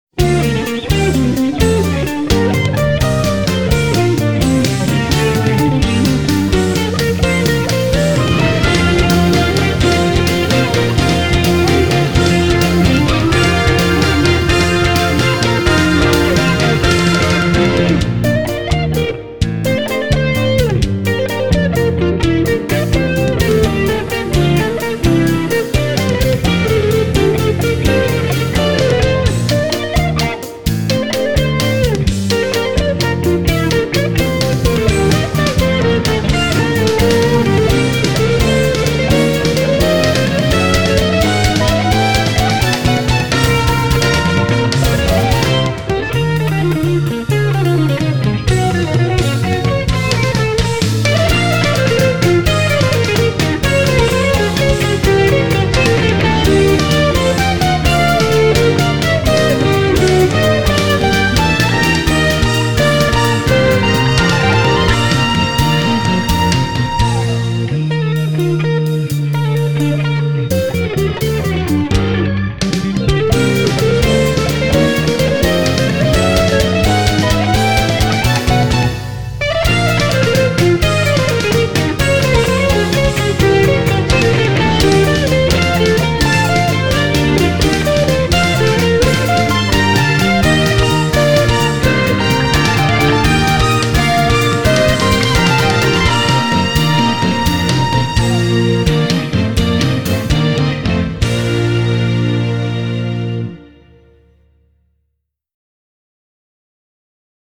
BPM256
Audio QualityPerfect (High Quality)
and one of my favorites from his instrumental songs.